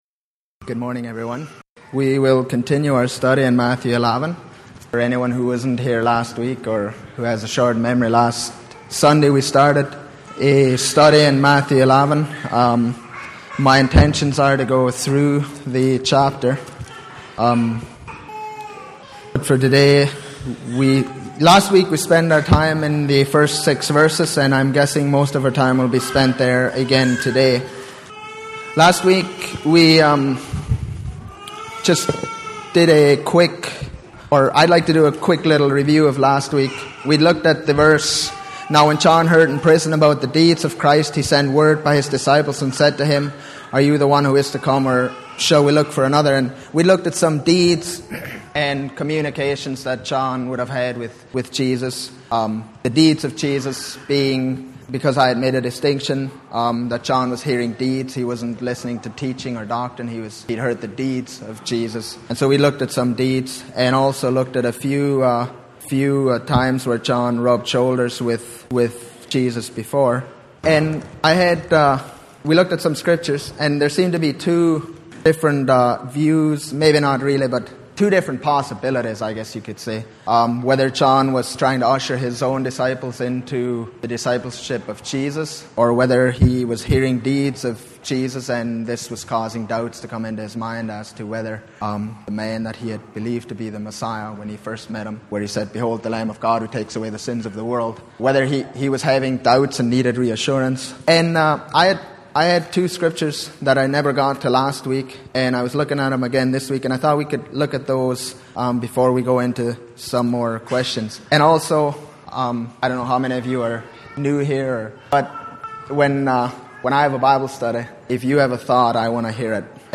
Sunday Morning Bible Study Passage